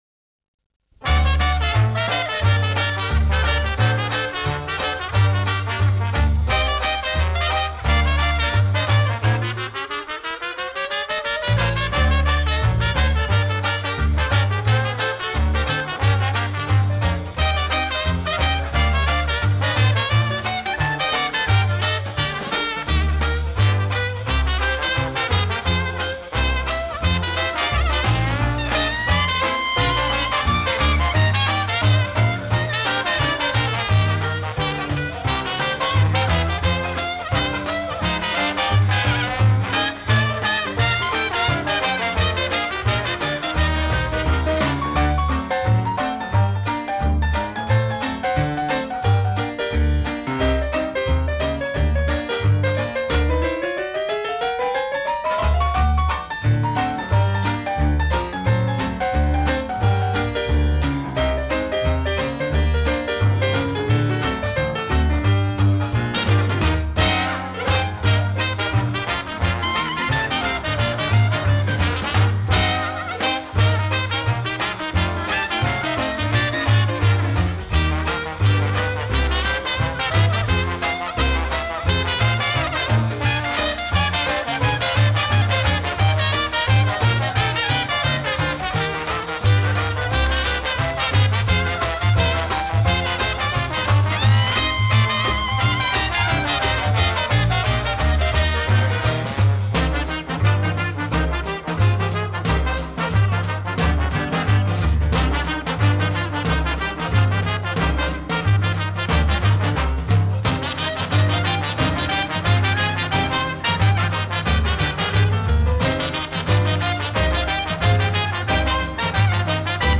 Веселые диксиленды послушаем .